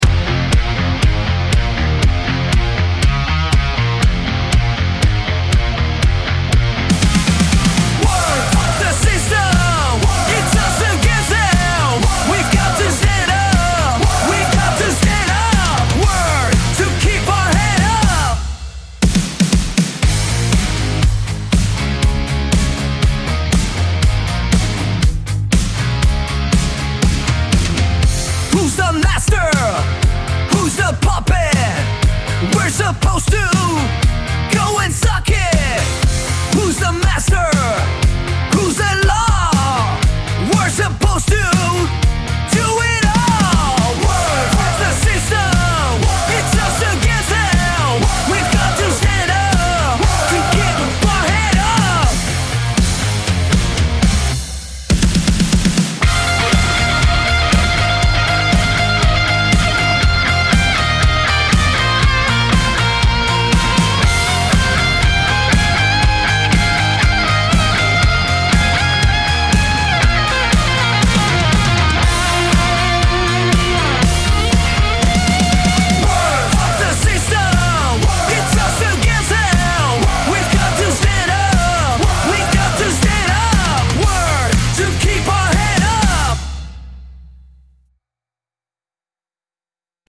Collaborative lyric writing with short, punchy hooks.
Generated track